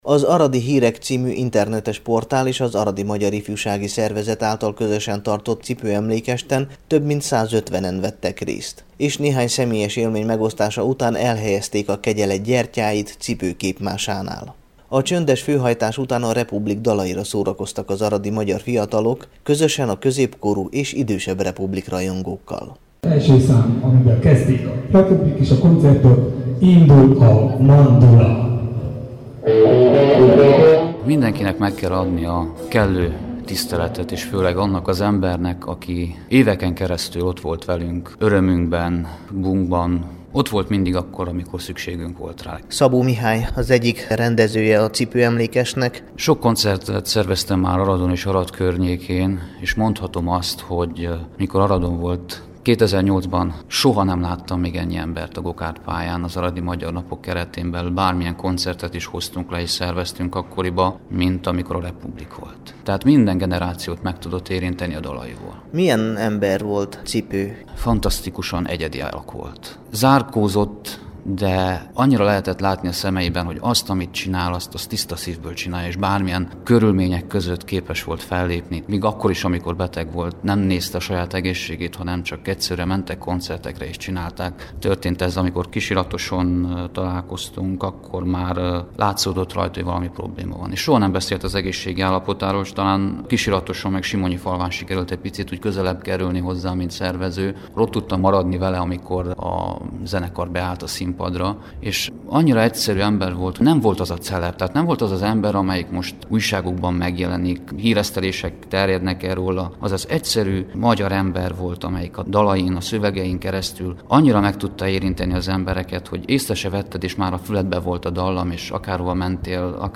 Bódi László temetése után egy nappal, 2013. március 23-án az aradi rajongók szívhez szóló Cipő-emlékestet tartottak. Az akkor készült riport meghallgatható itt.